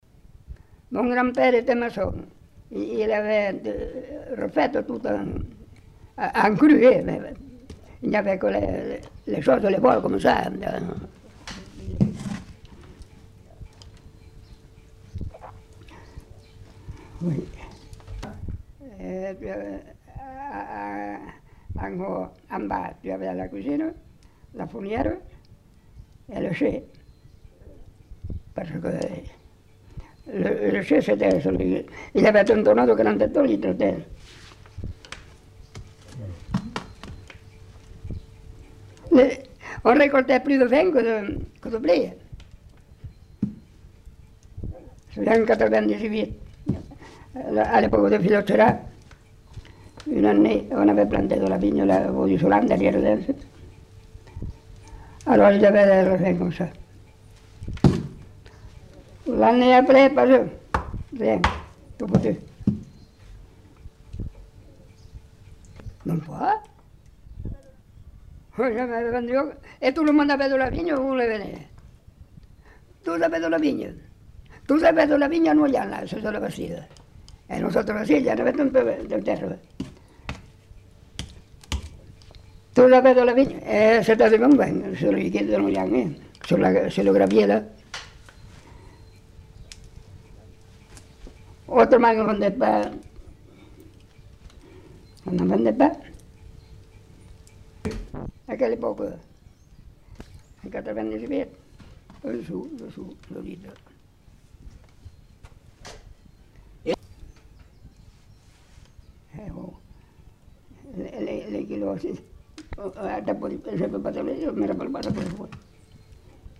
Lieu : Pompiac
Genre : témoignage thématique